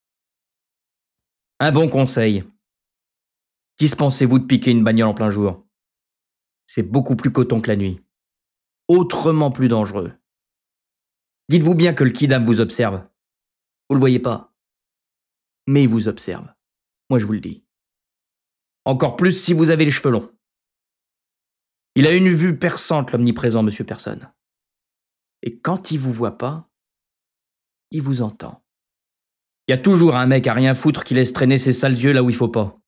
Les Valseuses Livre de Bertrand Blier (Extrait)